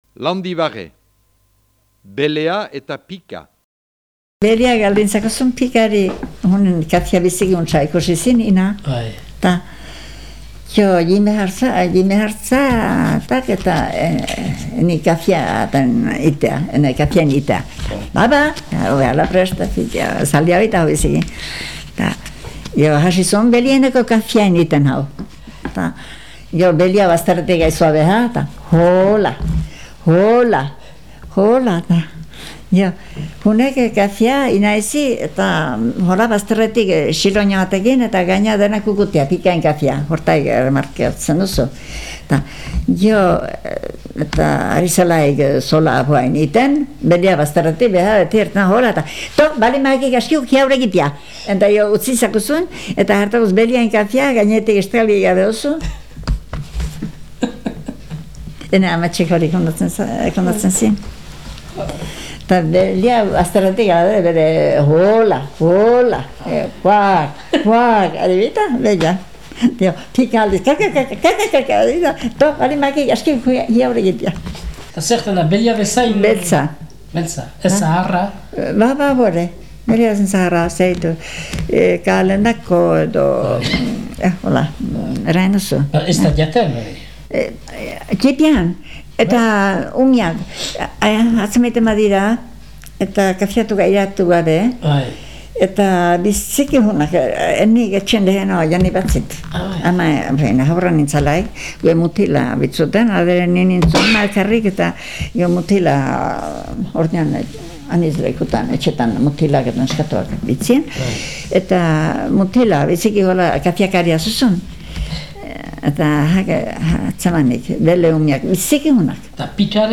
6.12. LANDIBARRE